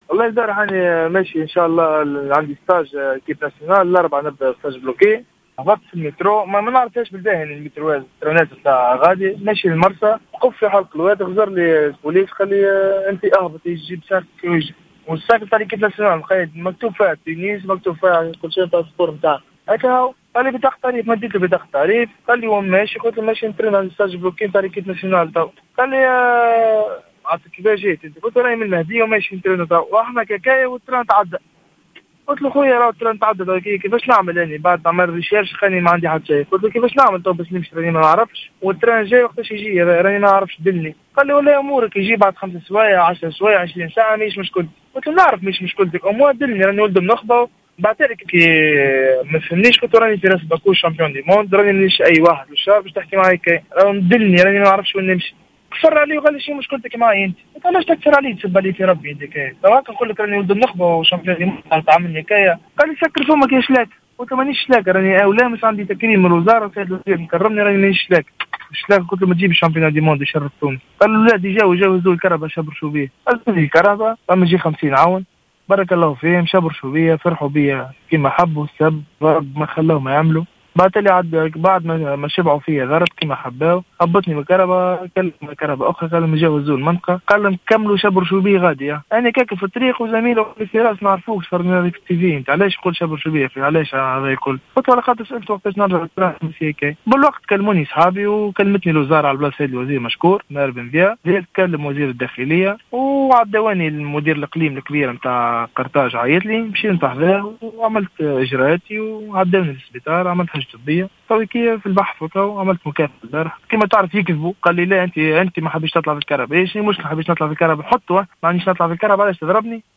تحدث بطل العالم في رياضة "الكيك بوكسينغ" فراس البكوش في تصريح لجوهرة أف أم حول حادثة الإعتداء عليه من طرف أعوان الأمن أمس السبت بمحطة القطار بحلق الوادي عند تنقله للالتحاق بالتربص المغلق المنتخب التونسي.